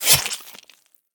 flesh3.ogg